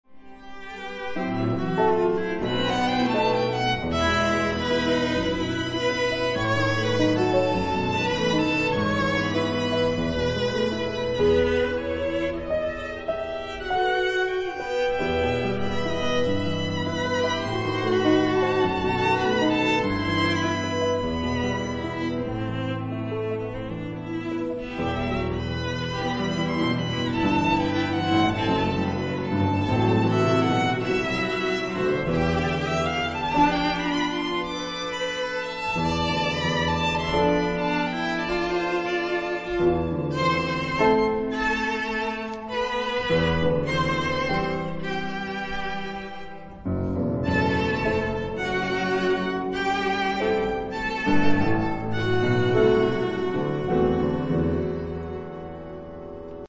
pel Natale" durante il Concerto Cameristico al Palacongressi di Loreto (aprile 2002)